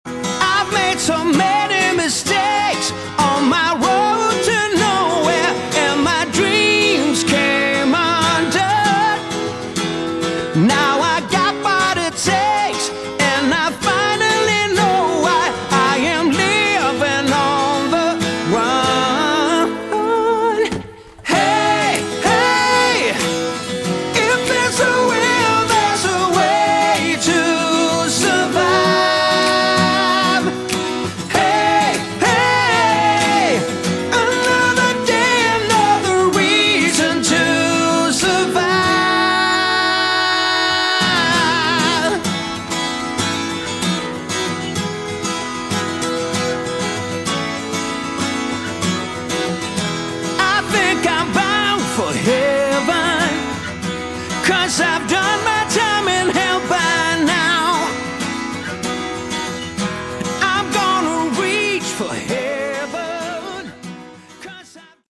Category: Melodic Rock
lead and backing vocals, bass
guitars, synthesizers, backing vocals, percussion
drums
grand Piano, background vocals
organ